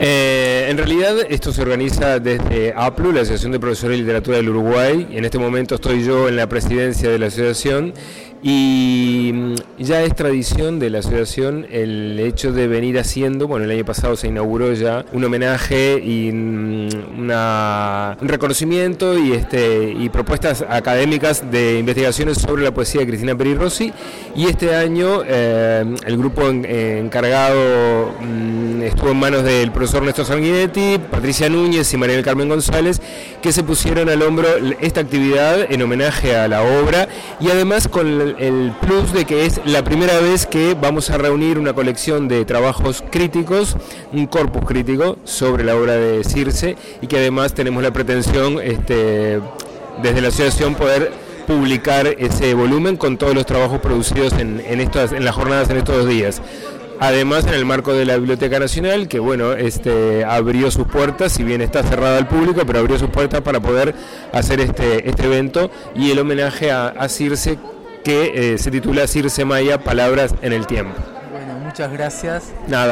Con él pudimos conversar brevemente sobre esta tan significativa actividad.